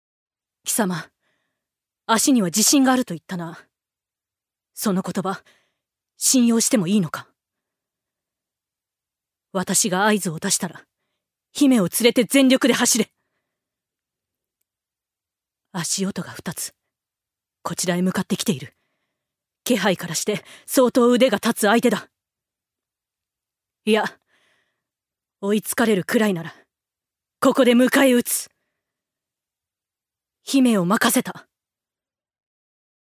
所属：男性タレント
セリフ３